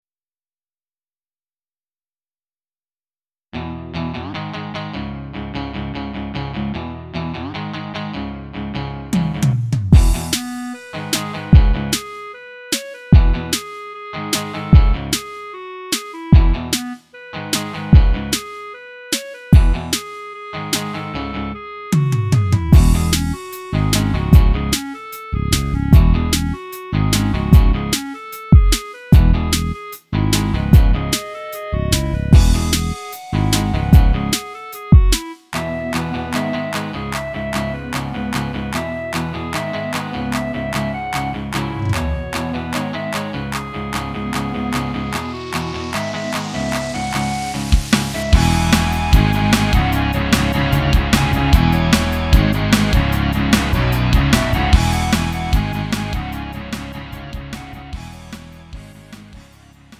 음정 -1키 2:50
장르 가요 구분